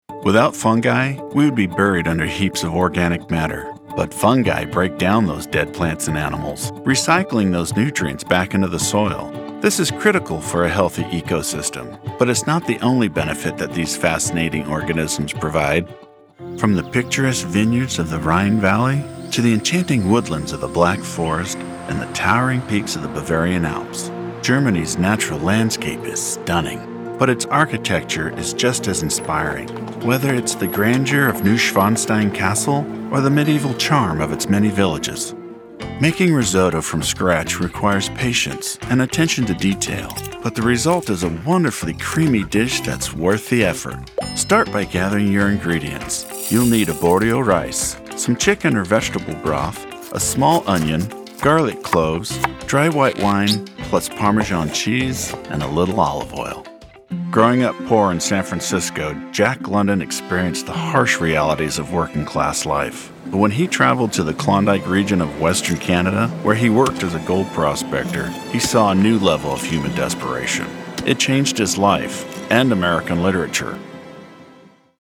commercial / Narration
I can offer a voice that can be smooth and laid back, to gruff and raspy.
Mostly standard American English with some variations in dialect. American western, standard southern accent, as well as standard British English.